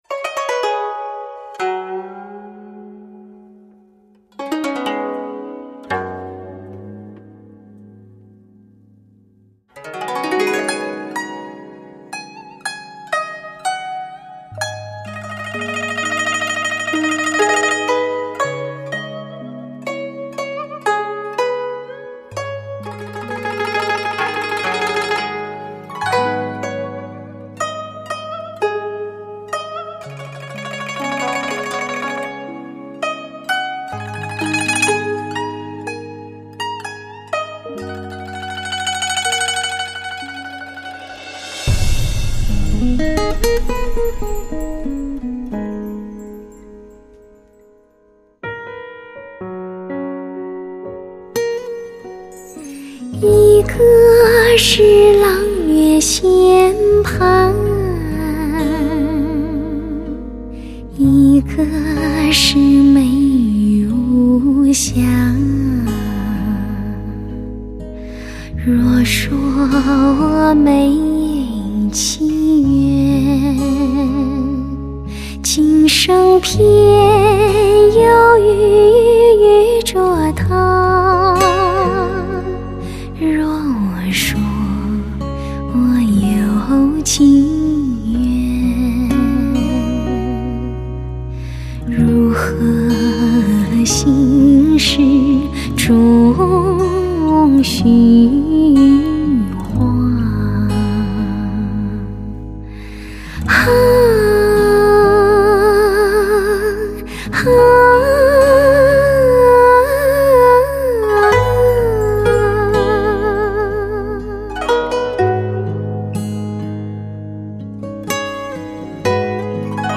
悠扬的古典曲调，首首严选精彩之作，自然的丰润音乐，满足您最严刻的标准，令你心醉神迷！